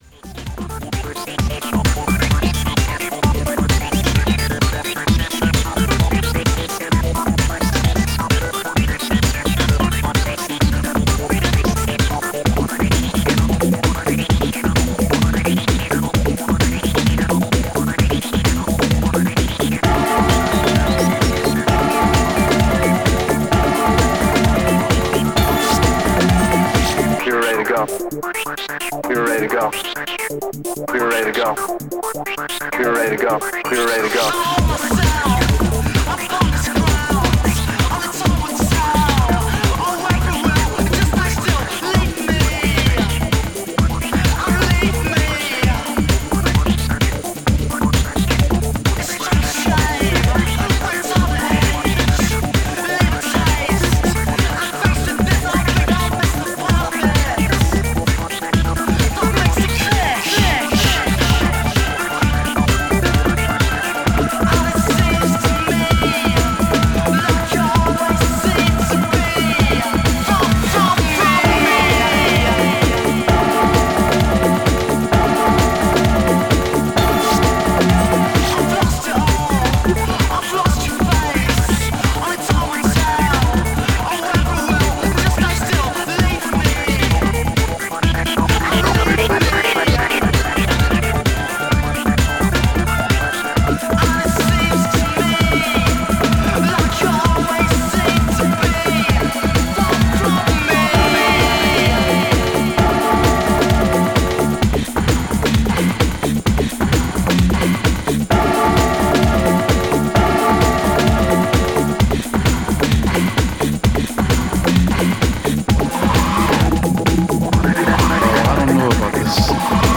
STYLE Breakbeat